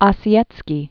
(ŏsē-ĕtskē, ôsē-), Carl von 1889-1938.